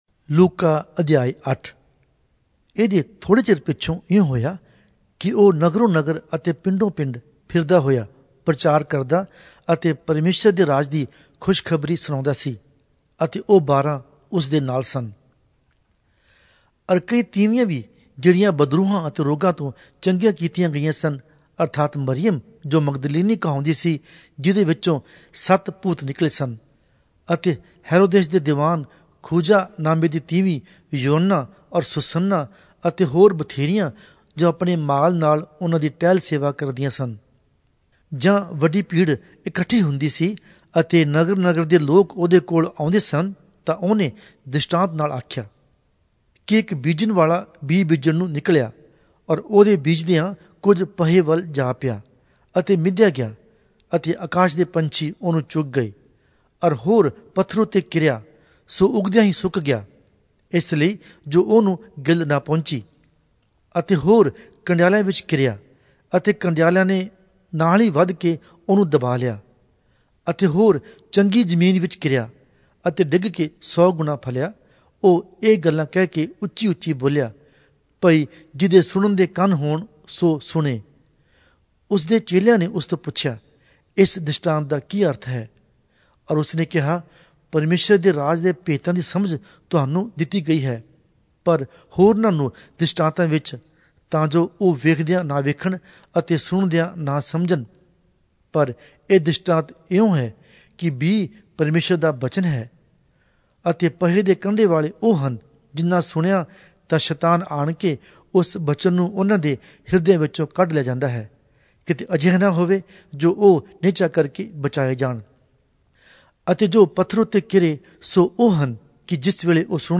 Punjabi Audio Bible - Luke 10 in Lxxen bible version